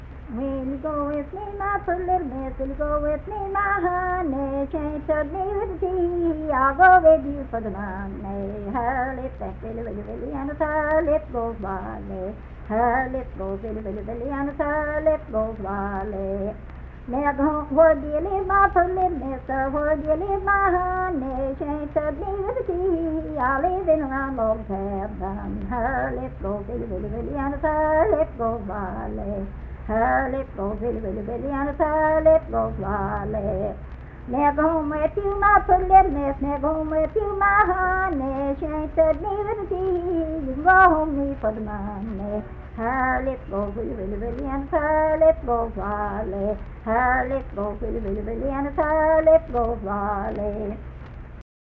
Unaccompanied vocal music performance
Verse-refrain 3(4) & R(2).
Bawdy Songs
Voice (sung)